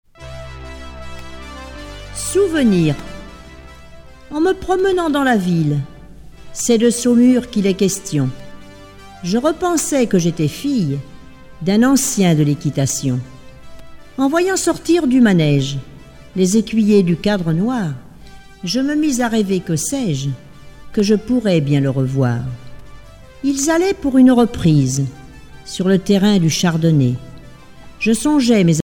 Genre poésie
Catégorie Récit